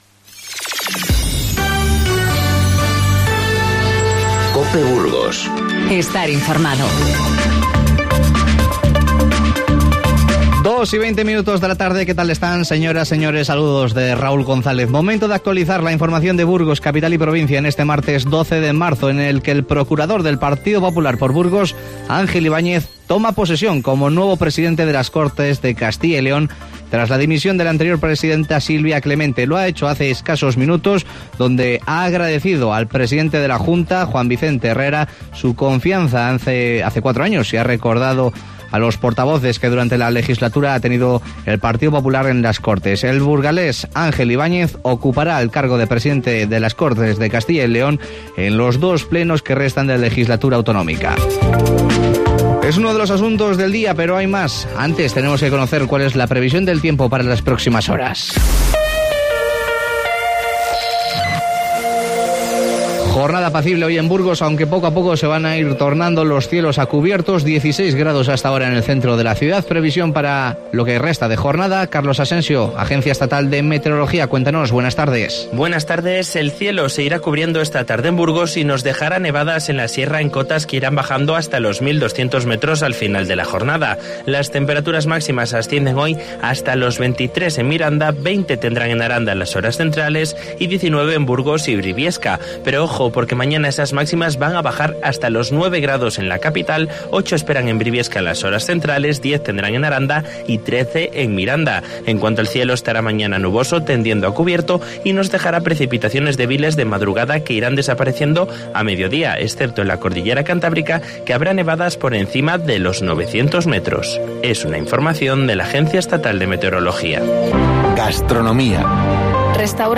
AUDIO: Escucha las noticias de hoy.